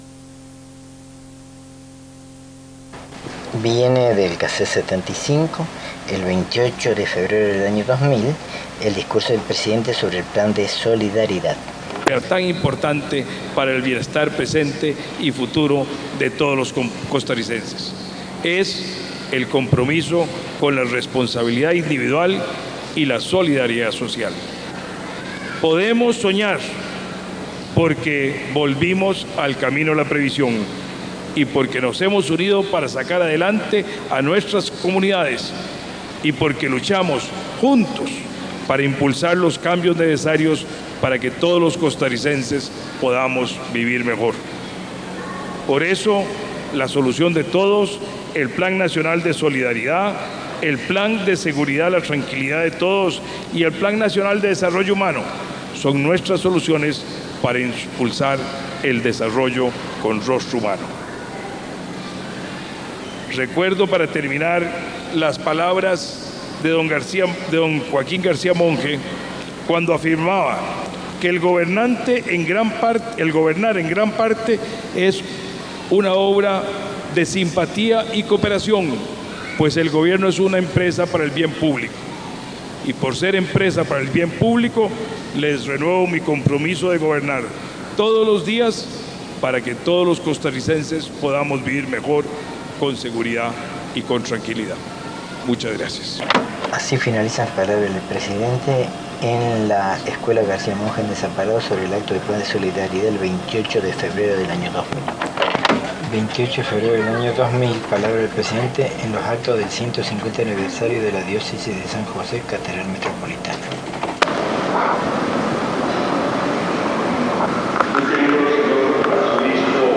Notas: Casette de audio